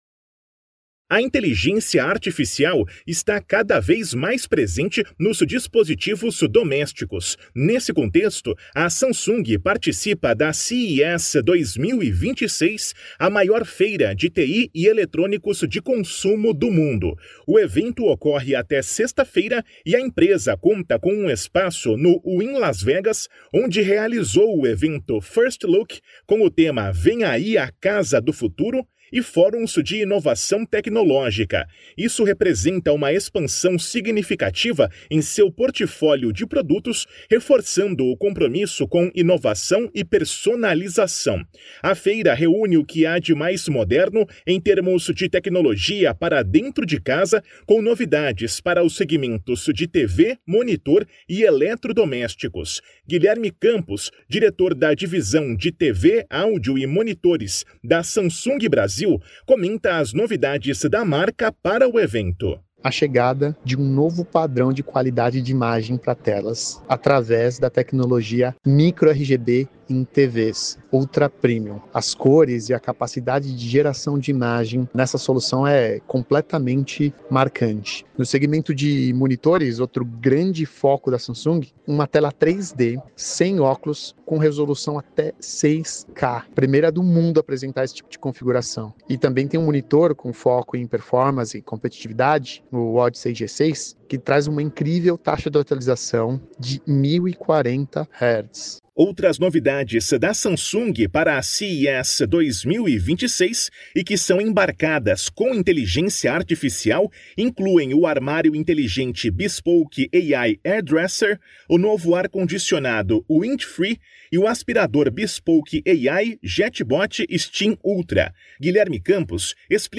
Materiais de Imprensa > Radio Release